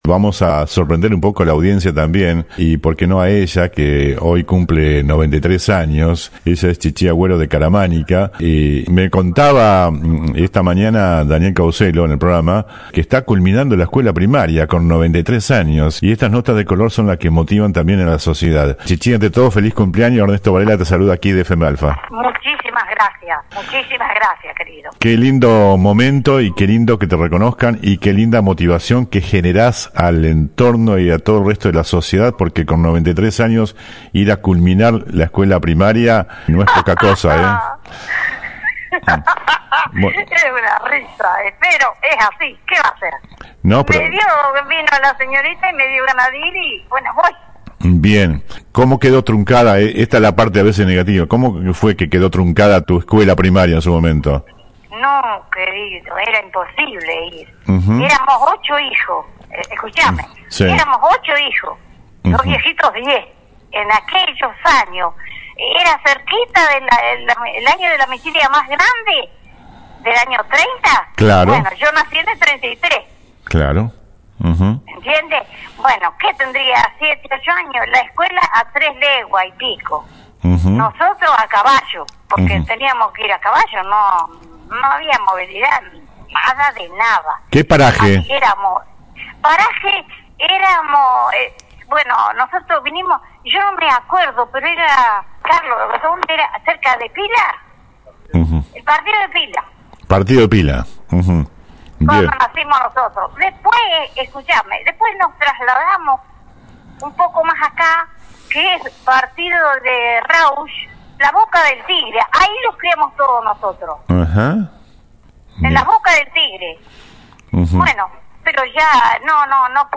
En una entrevista de color